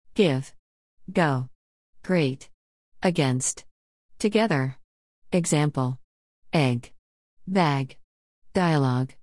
Áudio do nativo para Prática Agora a missão de estudo está com vocês, não deixem de praticar.